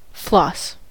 floss: Wikimedia Commons US English Pronunciations
En-us-floss.WAV